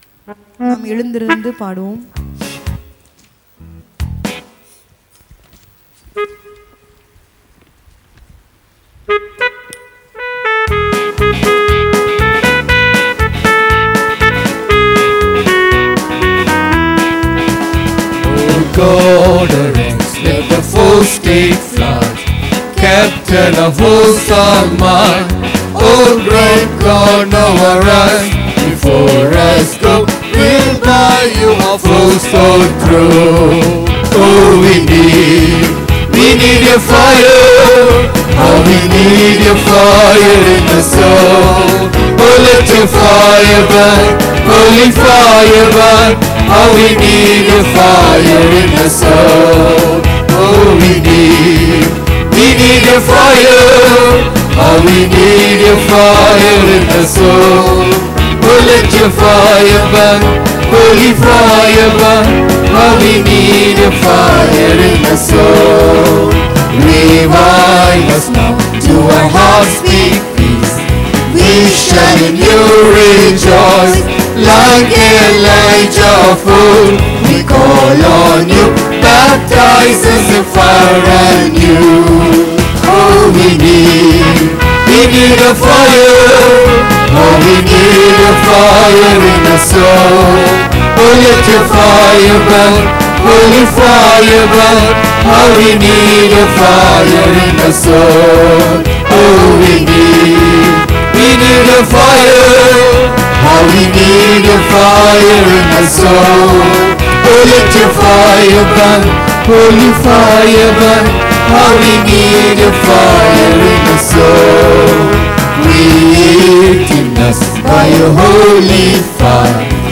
24 Sep 2023 Sunday Morning Service – Christ King Faith Mission
Download: Worship | Message Sunday Morning Service_Worship Sunday Morning Service_Message Matthew 6:24,25 No man can serve two masters: for either he will hate the one, and love the other; or else he will hold to the one, and despise the other.